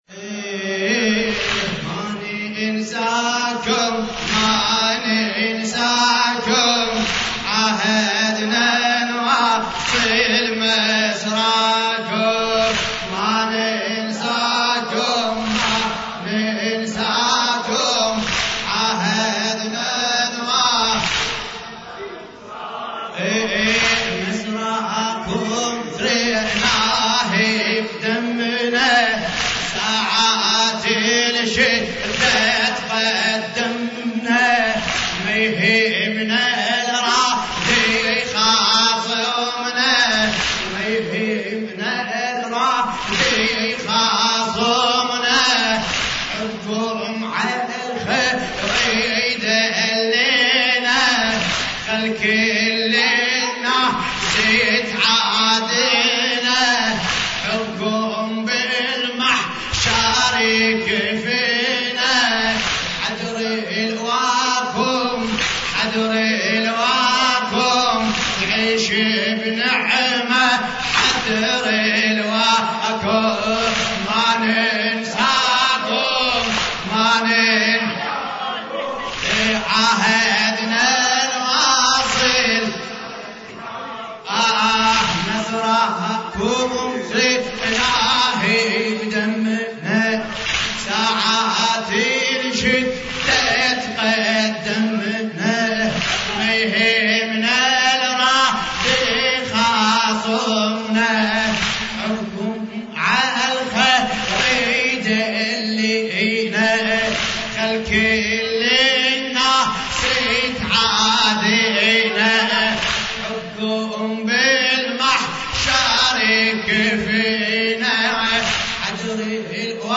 لطميات متفرقة